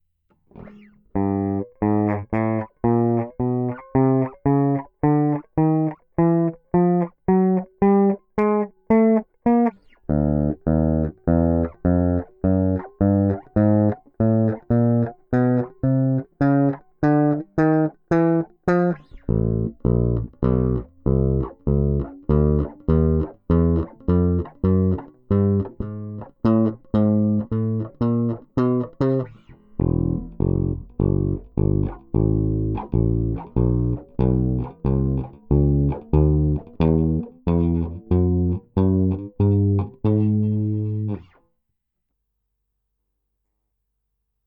Записано в квад только директ. Хотя звенит симпатично, и если снять еще микрофоном струны, мож и ничего)) Правда перегрузил кое-где.
Вложения Ноты баса.mp3 Ноты баса.mp3 1,7 MB · Просмотры